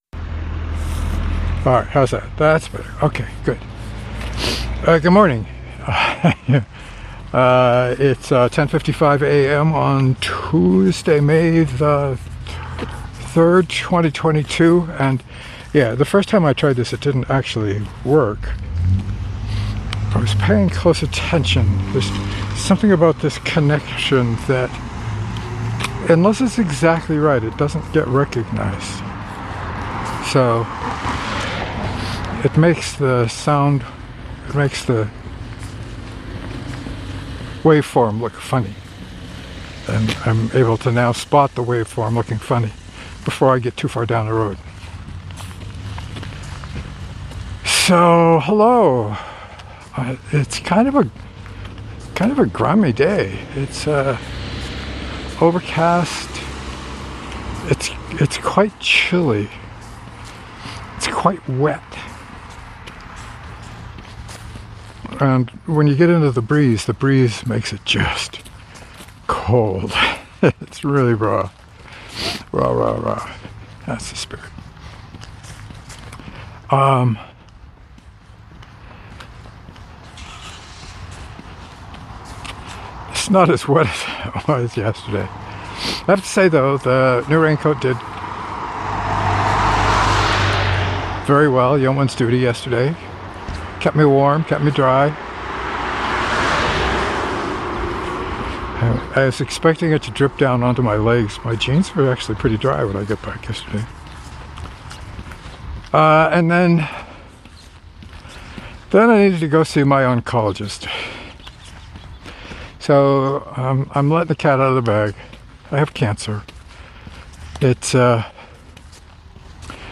I talked about the medical situation, the overcast day, and a bunch of bird and boot sounds, probably.